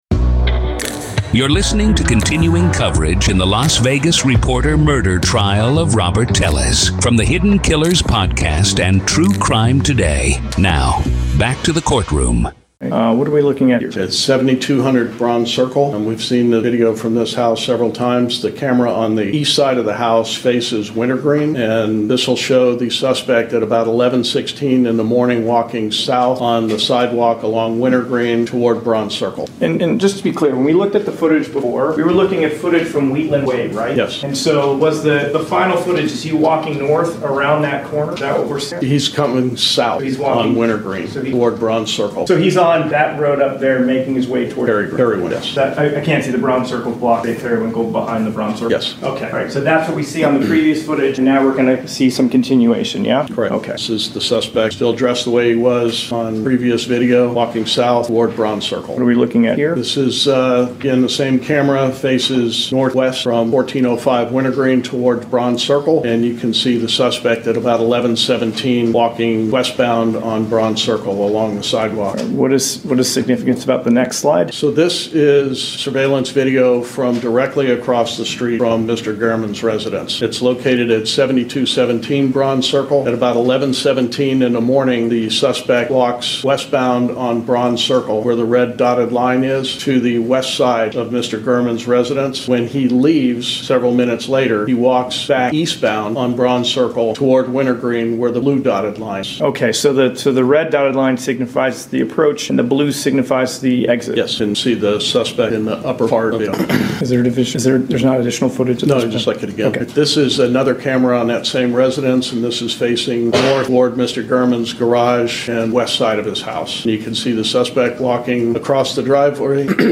Court Audio-NV v. Robert Telles DAY 1 Part 5